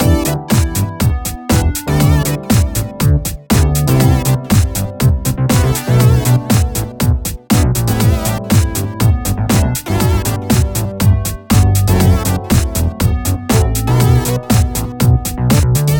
34  Full Mix.wav